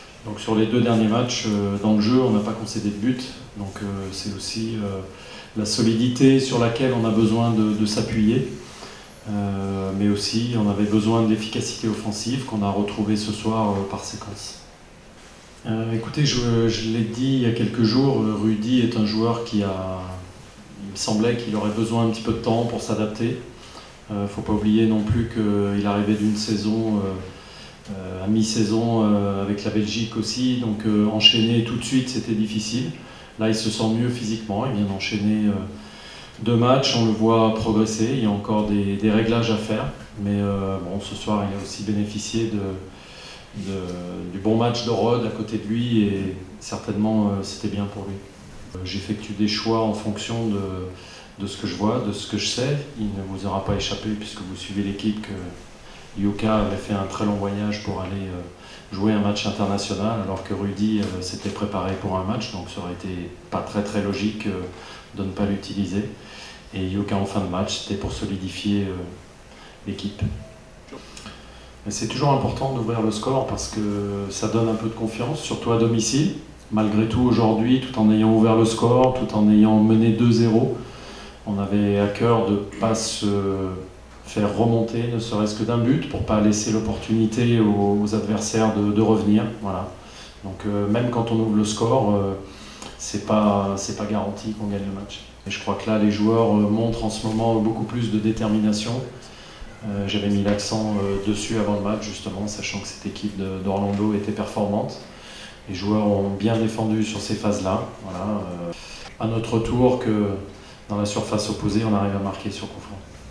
Interviste post partita: